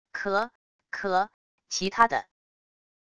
咳……咳……其他的wav音频